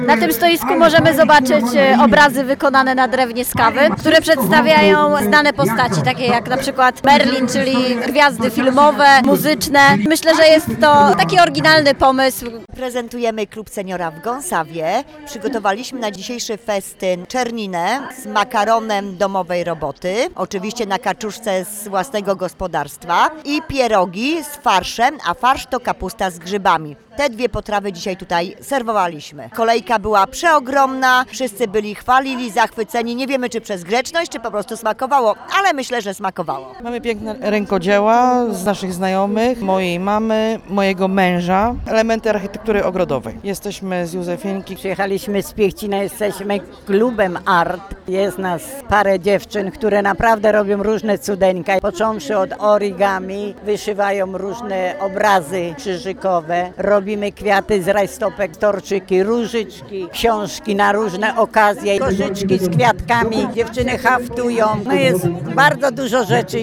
Biesiadnikom czas umilały ludowe przyśpiewki, wykonywane przez okoliczne kapele.
2014-festyn-ludowy.mp3